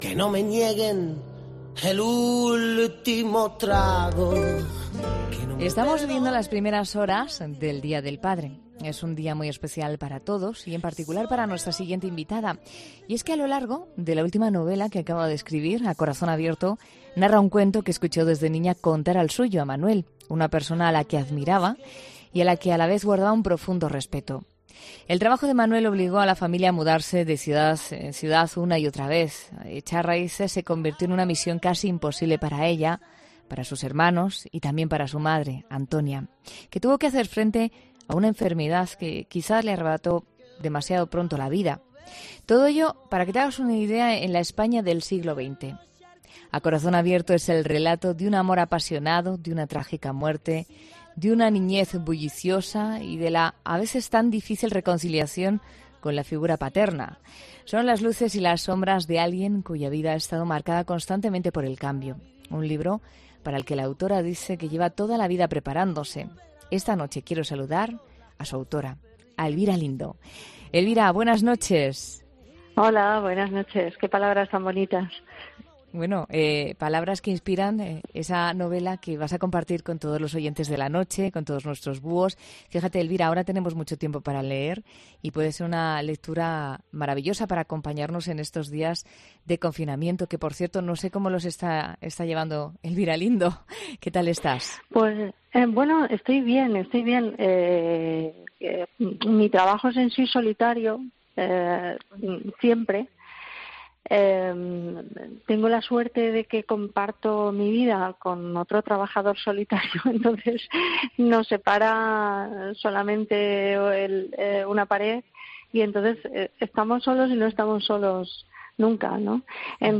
La escritora gaditana habla en 'La Noche' de COPE sobre su última novela